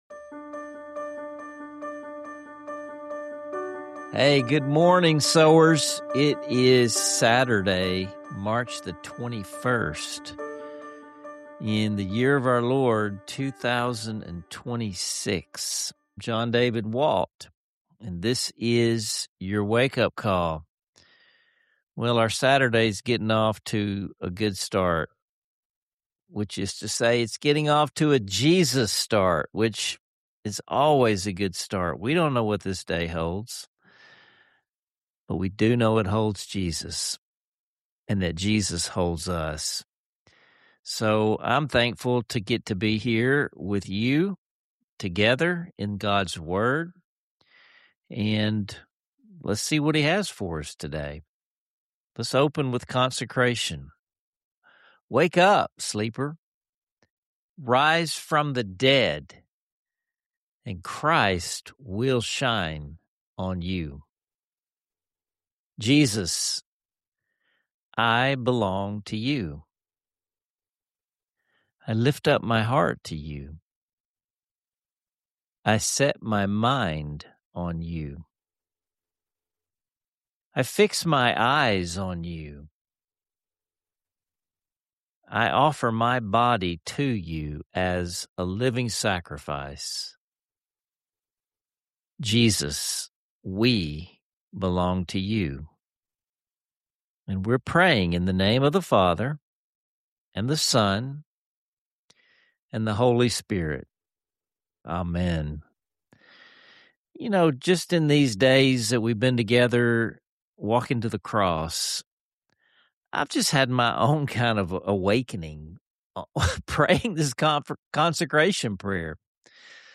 Plus, there’s a special moment of communal singing that underlines the episode’s core message: it’s not about performing for God, but beholding His glory and allowing that to shape who we are.